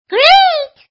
gameSuccess.mp3